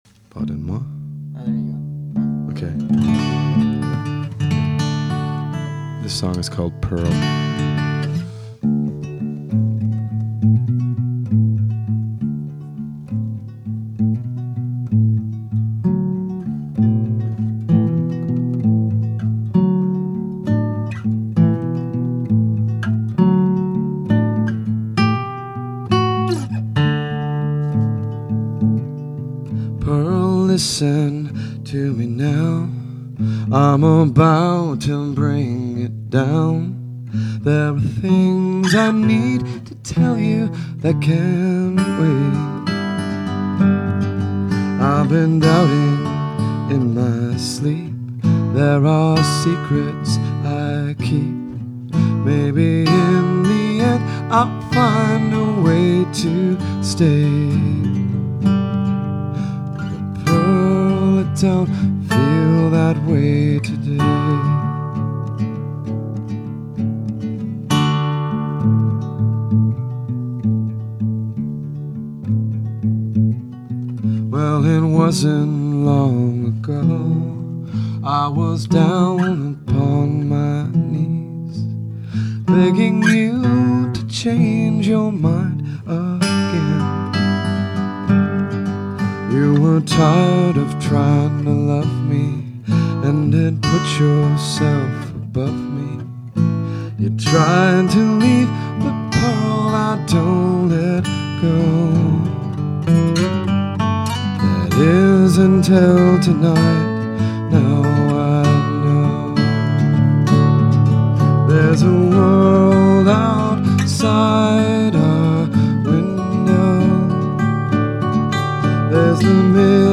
chant guitare
concert exclusif